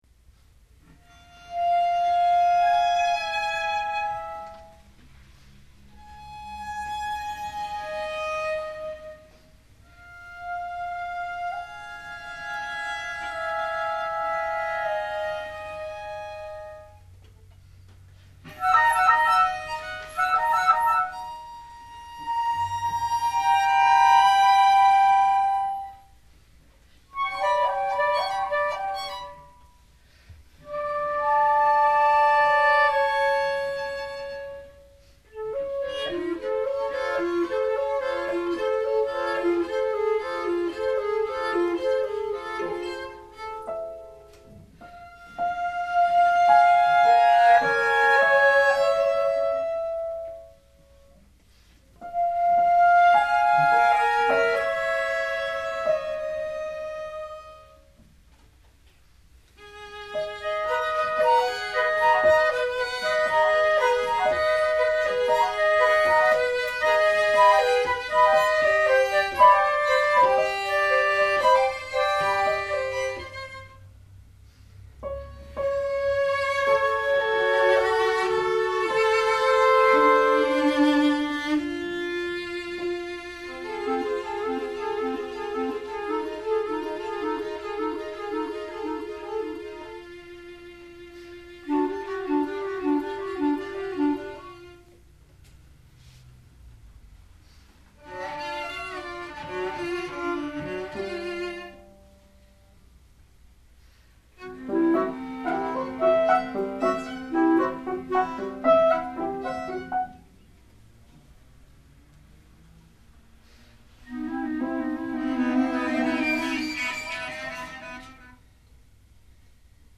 Both showed strong lyric and rhythmic development.